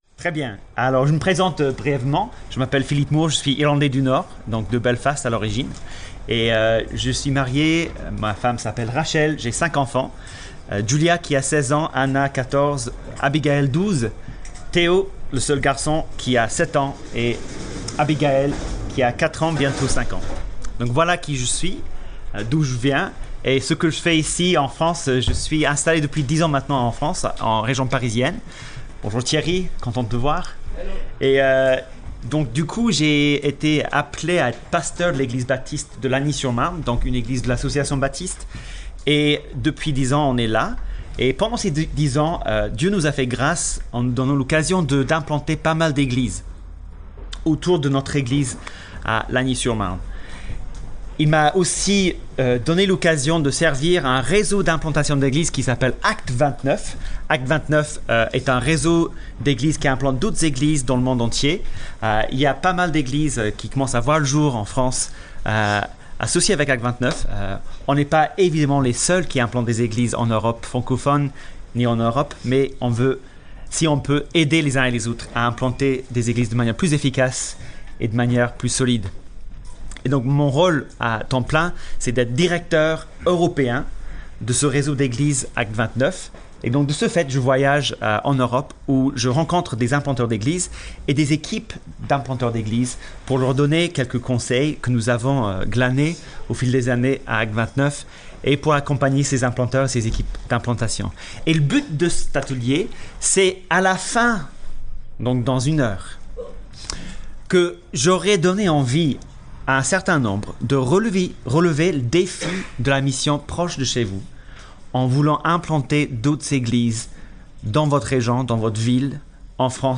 Pâques 2019 – Ateliers